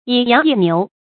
成語注音 ㄧˇ ㄧㄤˊ ㄧˋ ㄋㄧㄨˊ
成語拼音 yǐ yáng yì niú
以羊易牛發音